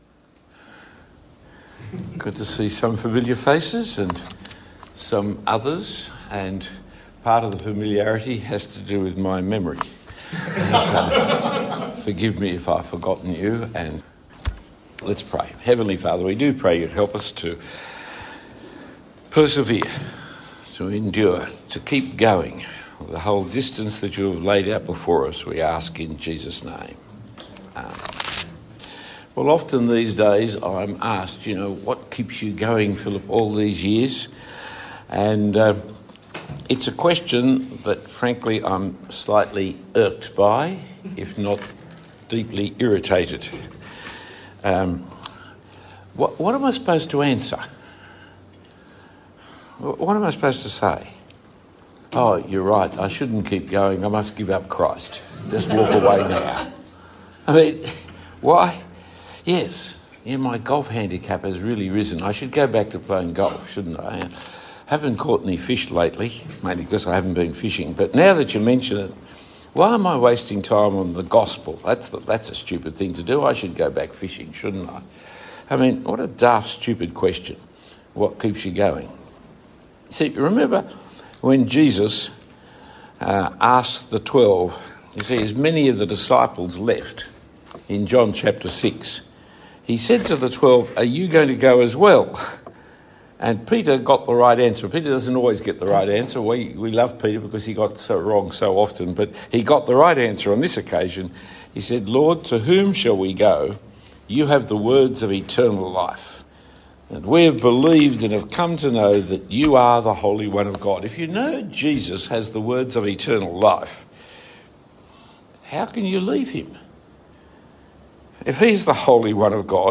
This talk was given for ministry workers.